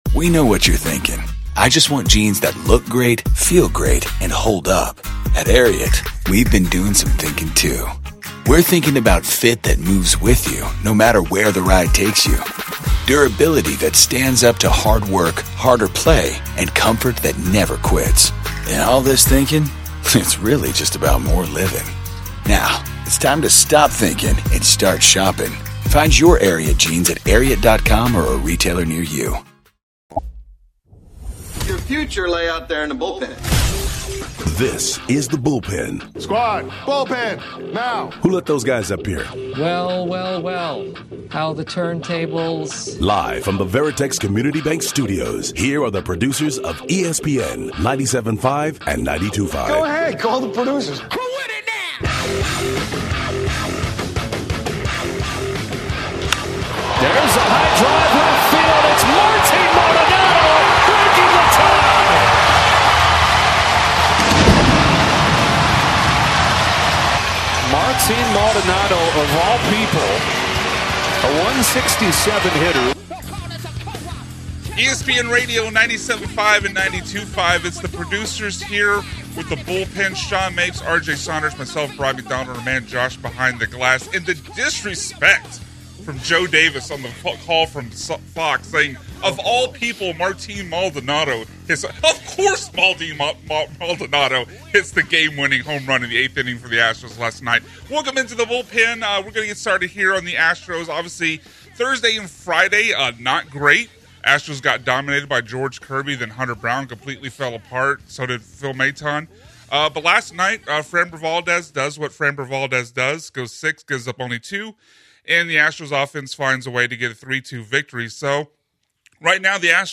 the trio discuss the latest in the sports world this week.